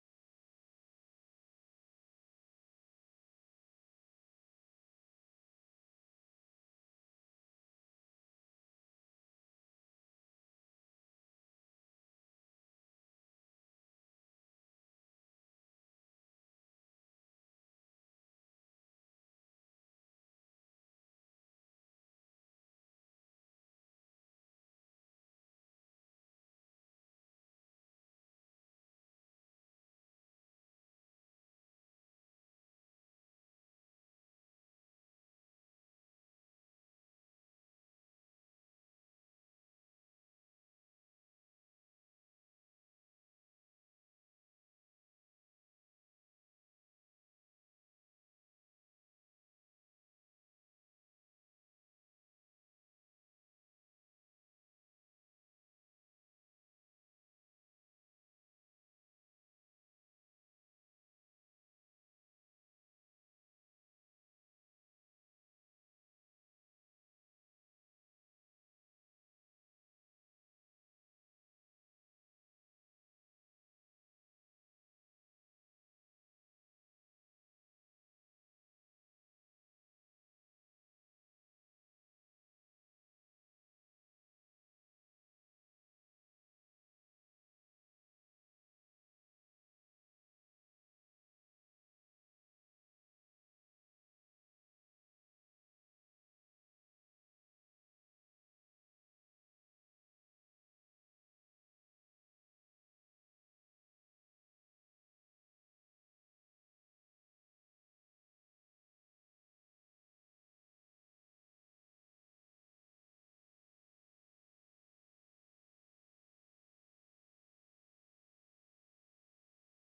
Praise & Worship at GLFWC on February 22 2026
Join us for our weekly service in-person or online starting at 10 A.M. every Sunday.